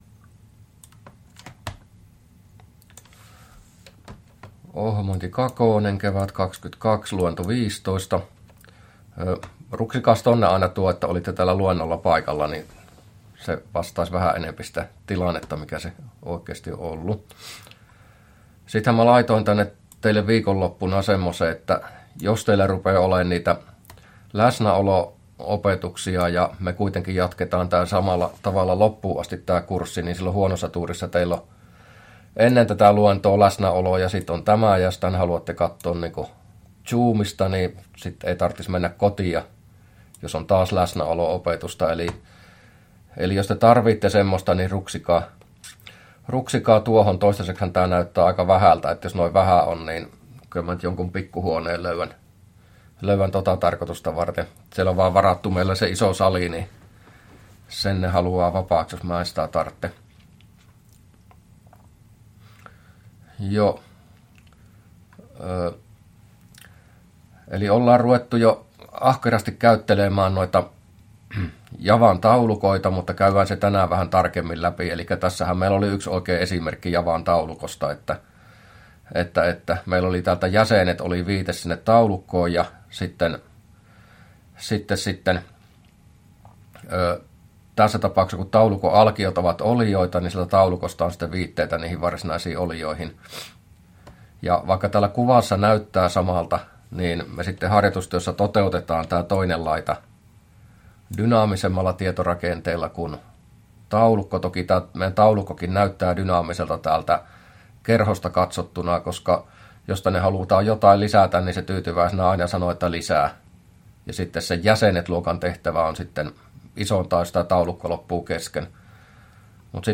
luento15a